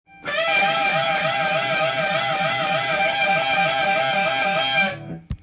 使いやすいようにAmペンタトニックスケールで譜面にしてみました。
そして、エレキギター特有のチョーキングを多用しているのも